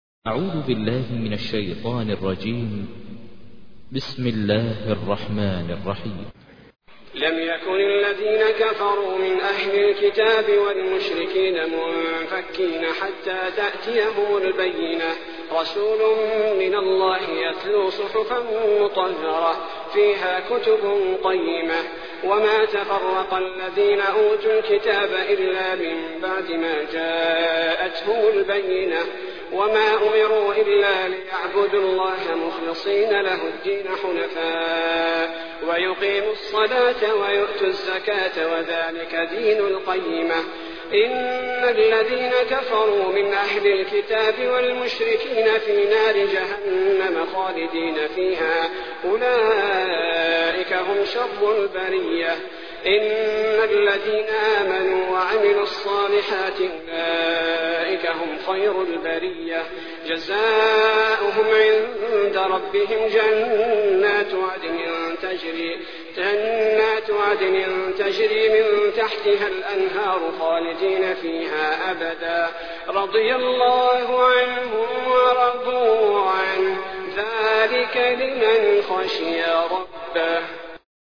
تحميل : 98. سورة البينة / القارئ ماهر المعيقلي / القرآن الكريم / موقع يا حسين